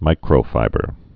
(mīkrō-fībər)